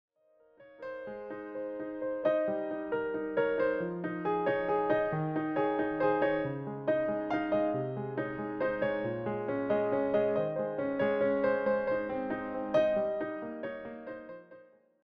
piano arrangements centered on winter and seasonal themes